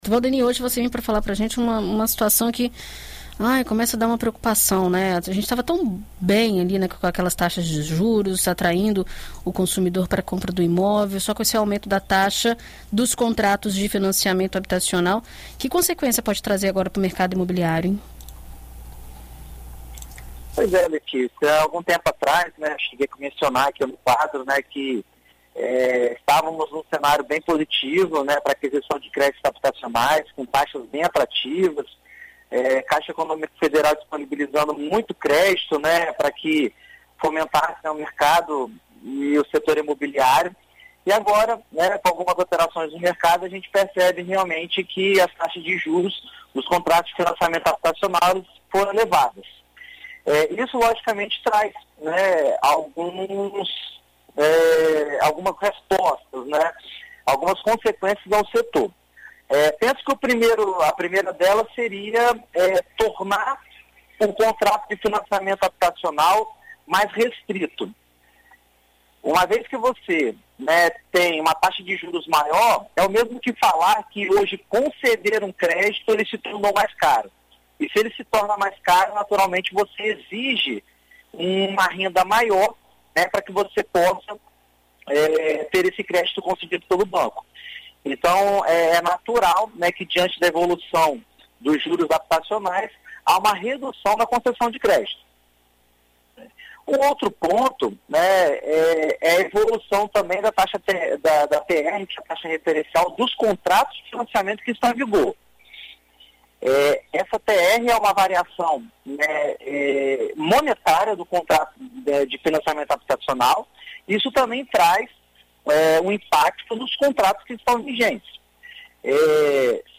Na coluna Seu Imóvel desta terça-feira (22), na BandNews FM Espírito Santo